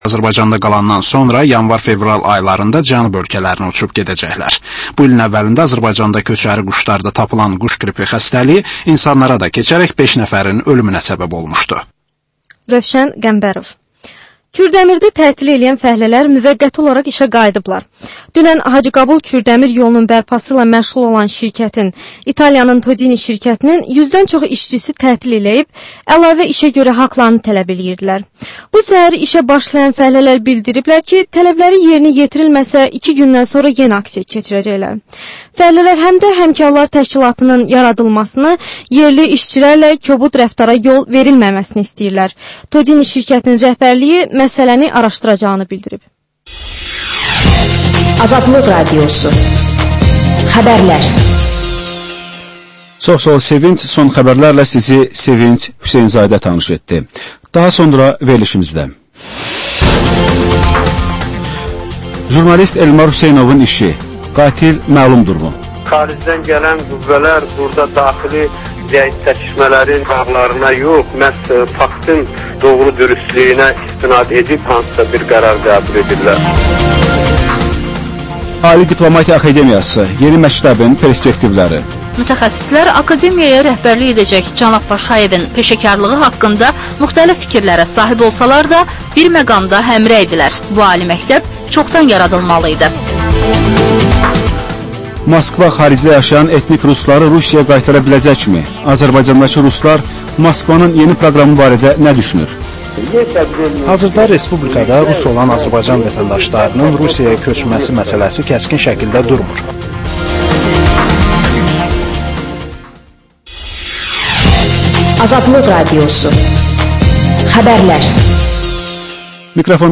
Hadisələrin təhlili, müsahibələr, xüsusi verilişlər.